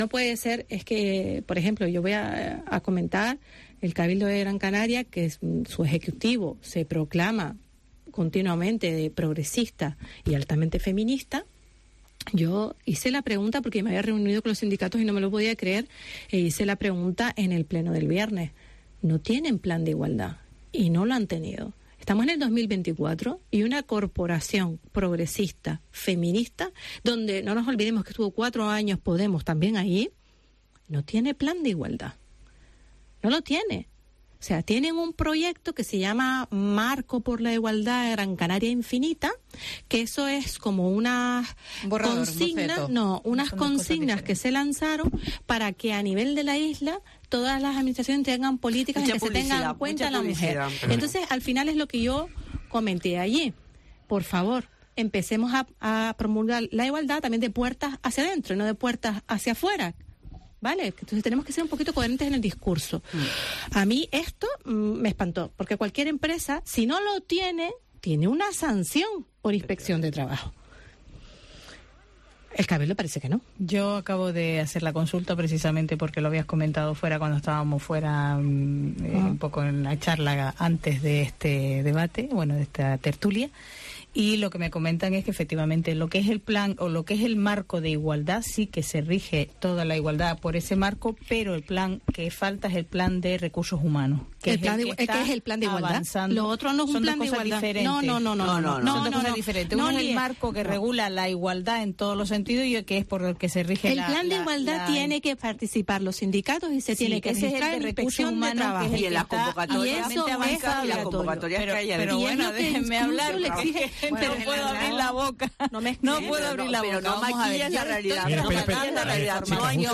Vidina Cabrera, consejera de Coalición Canaria en el Cabildo de Gran Canaria
La consejera de Coalición Canaria en el Cabildo de Gran Canaria, Vidina Cabrera, ha denunciado en Herrera en COPE Gran canaria que la institución insular no tiene plan de igualdad, a días para que se celebre el día de la mujer, Cabrera ha tildado de hipocresía las políticas de igualdad del Cabildo, “su ejecutivo se proclama continuamente progresista y feminista, hice la pregunta en el pleno y no tienen plan de igualdad y no lo han tenido”.